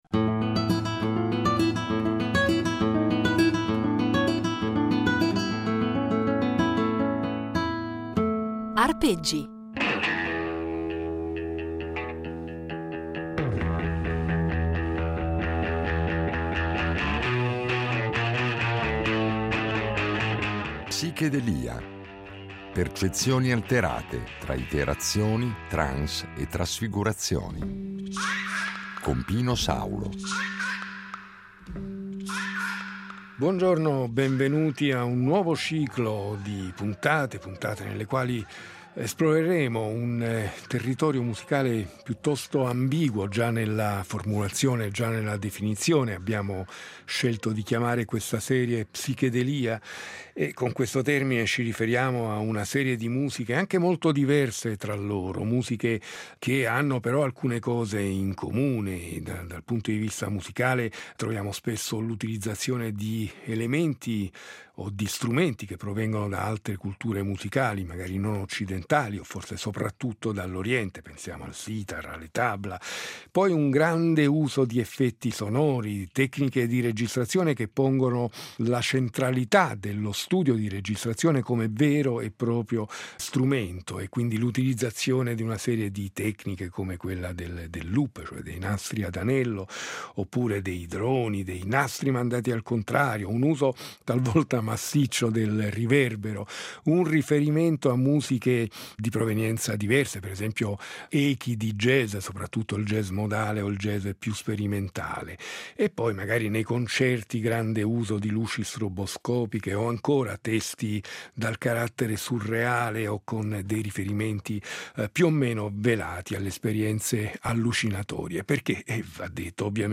Percezioni alterate tra trance, ripetizioni e trasfigurazioni